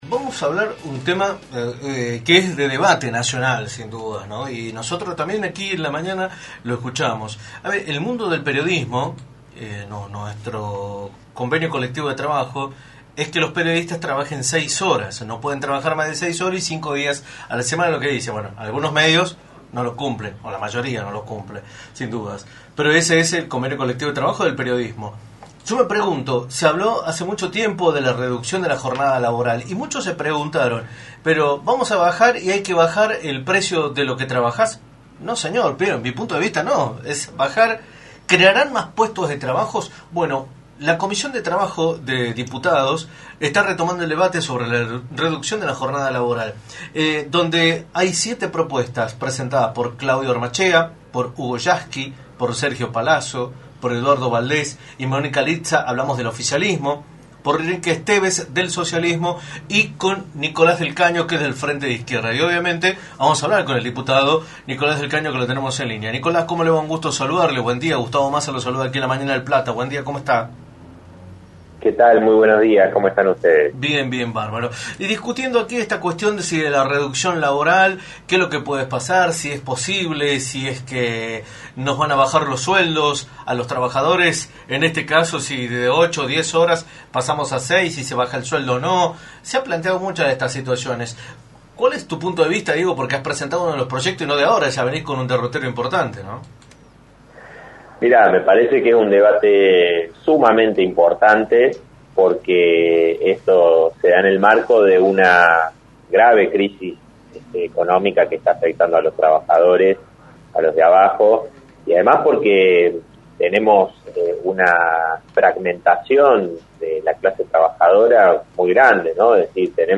Nicolás Del Caño, candidato a Vicepresidente por el Frente de Izquierda, analizó en Radio del Plata Tucumán, por la 93.9, cuáles son las cuestiones a tener en cuenta en relación al debate en el Congreso por la reducción del horario laboral.